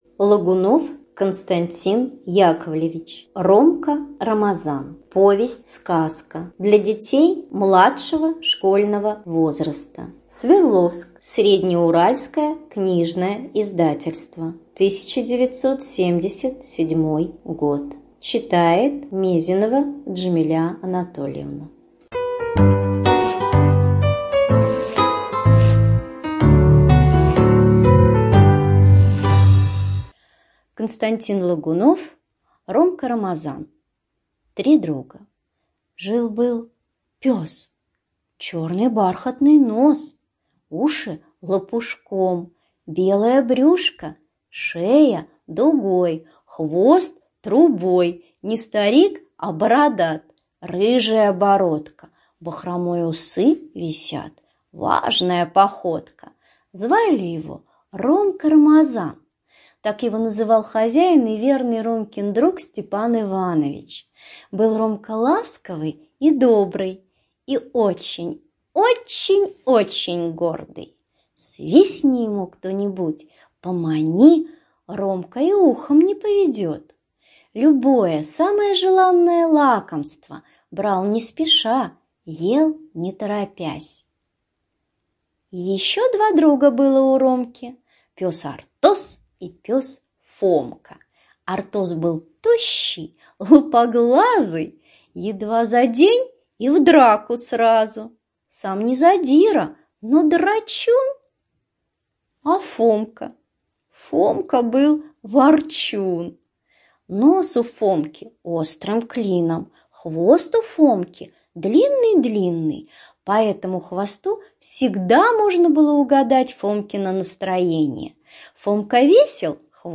Студия звукозаписиТюменская областная библиотека для слепых